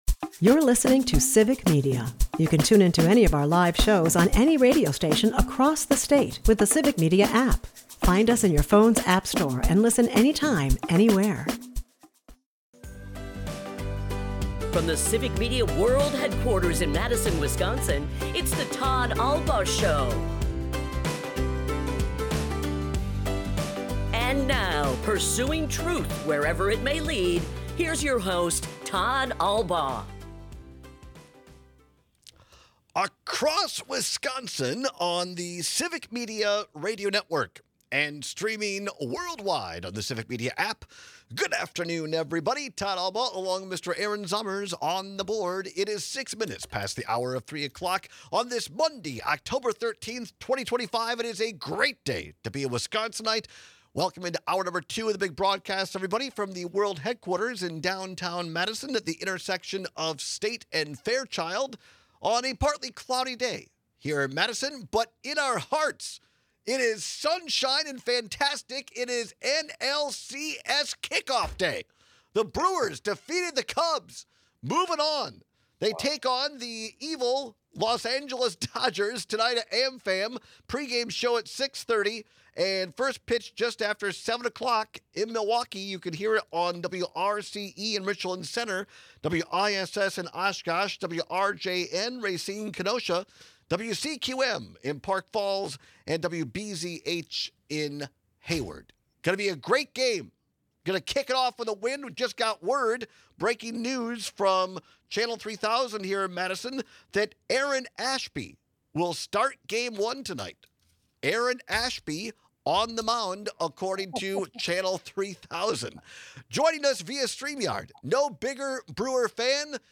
We take your texts and calls on which one is more difficult to deal with.